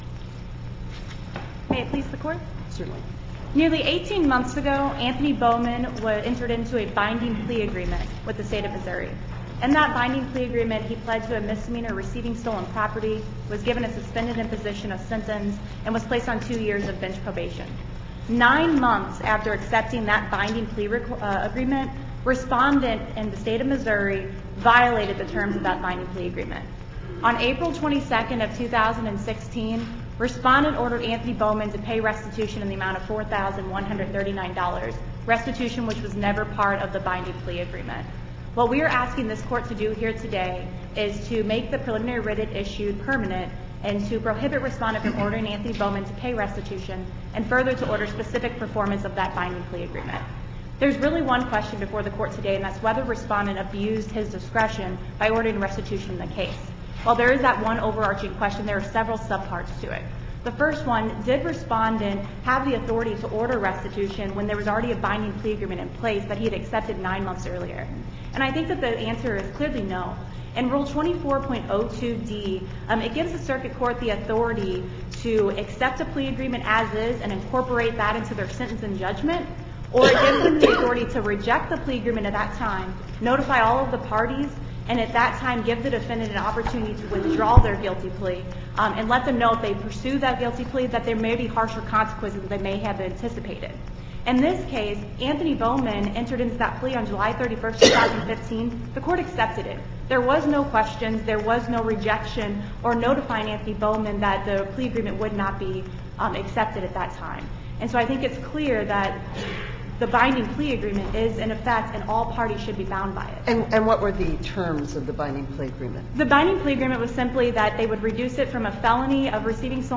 MP3 audio file of arguments in SC95783